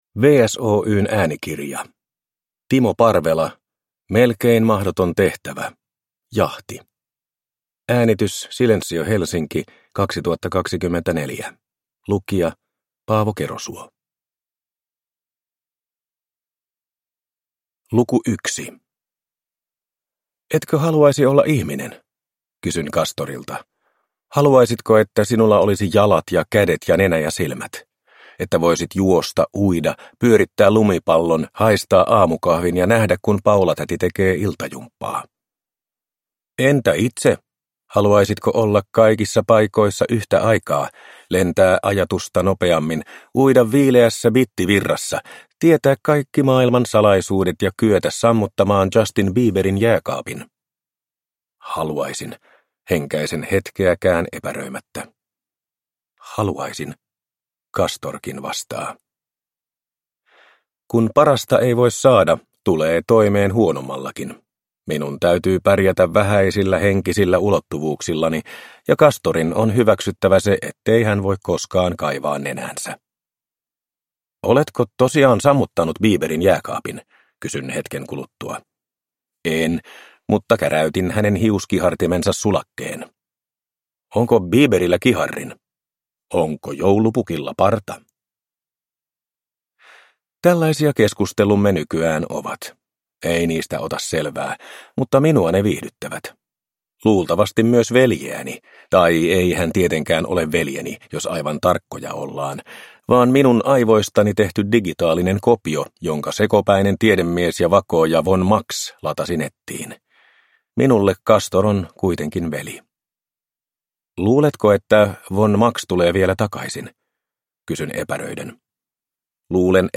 Melkein mahdoton tehtävä - Jahti – Ljudbok